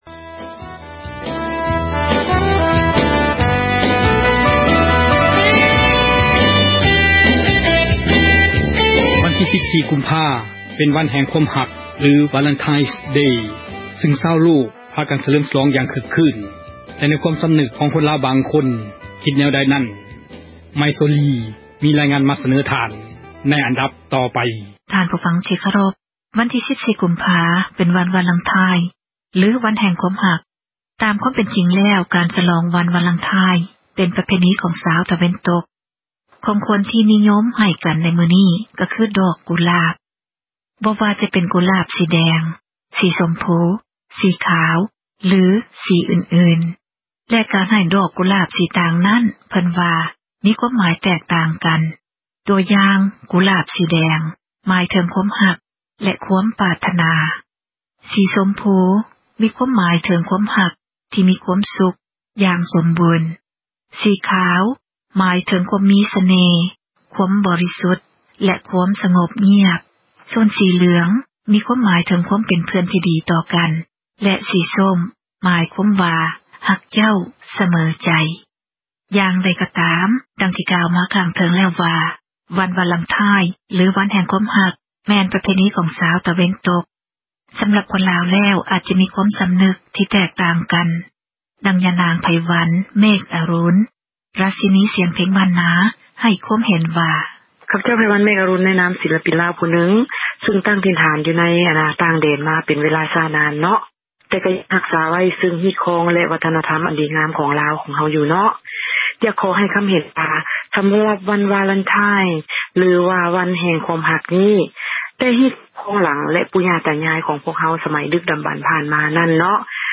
ວັນທີ 14 ກຸມພາ ເປັນວັນ ແຫ່ງຄວາມຮັກ “Valentine's Day” ຊຶ່ງຊາວໂລກ ສ່ວນຫລາຍ ພາກັນ ສລອງ ຢ່າງຄຶກຄື້ນ ເຊີນທ່ານຟັງ ການສໍາພາດ ຄົນລາວ ບາງຄົນ ວ່າຄິດ ແນວໃດ ໃນວັນດັ່ງກ່າວ.